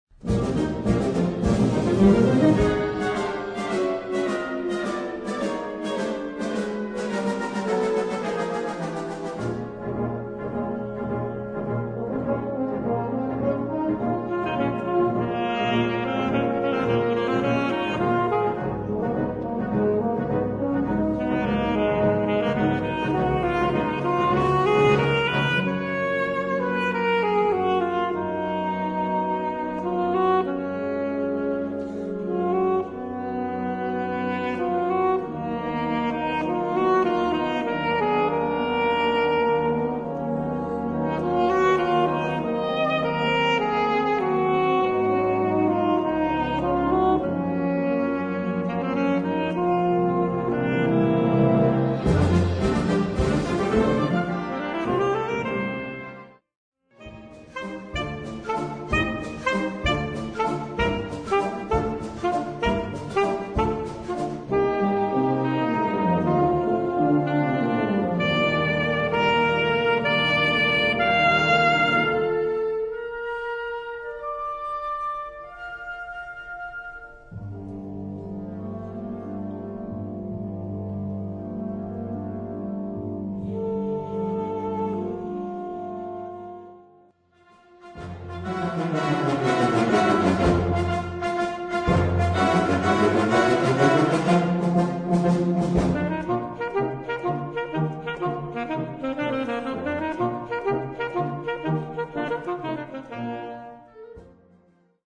Gattung: Solo für Altsaxophon, Flöte oder Klarinette
Besetzung: Blasorchester